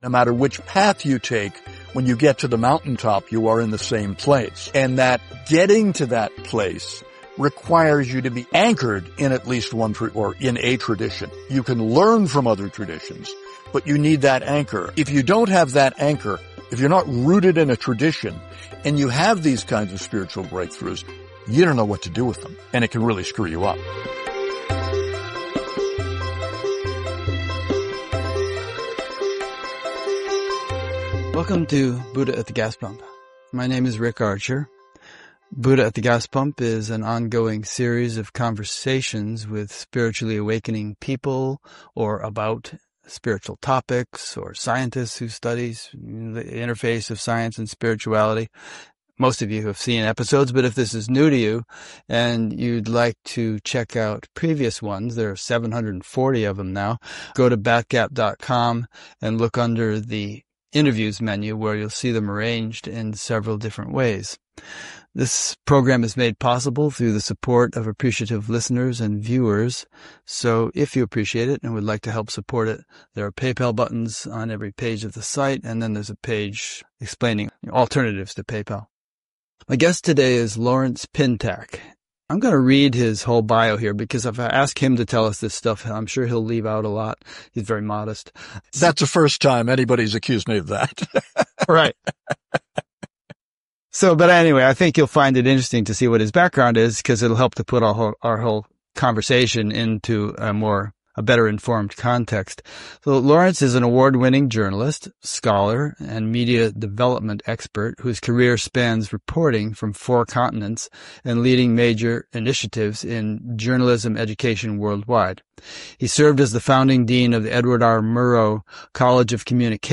Transcript of this interview Interview recorded October 4, 2025